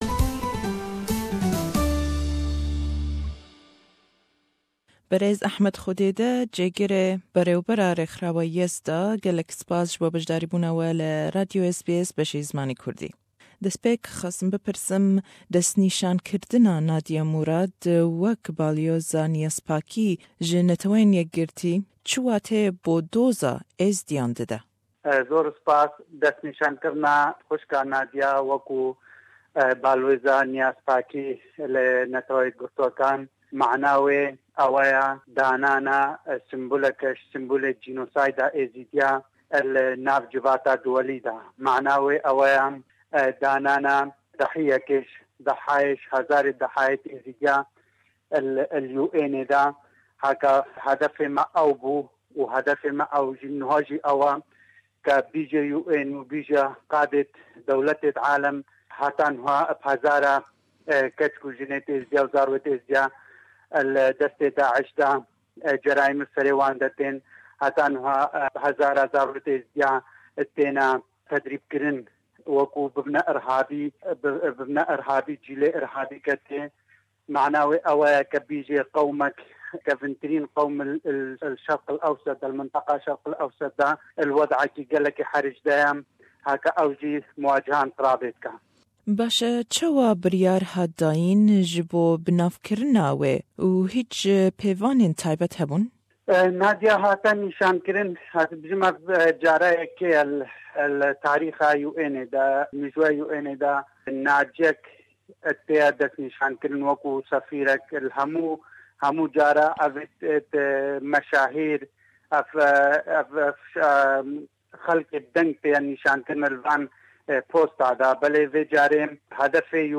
Le em hevpeyvîne